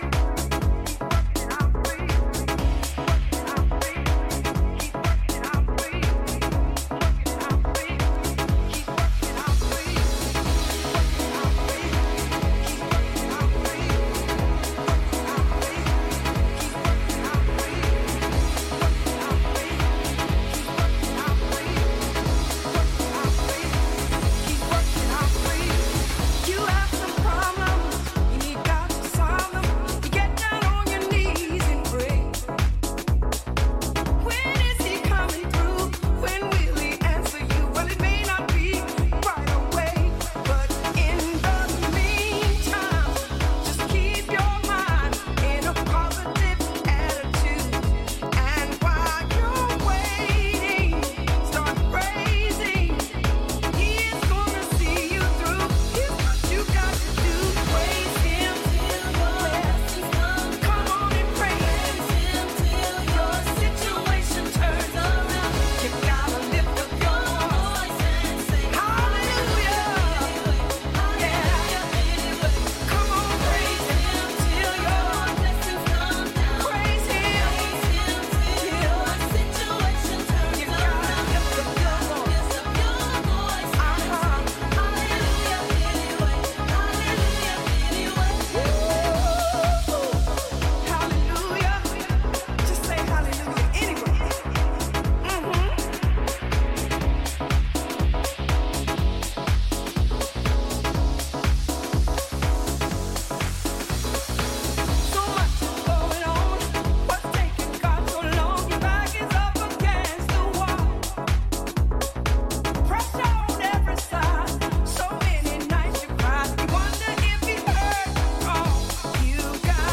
今後、定番的に楽しめるだろうオーセンティックなソウル感溢れる歌物ハウス集です！
ジャンル(スタイル) HOUSE / SOULFUL HOUSE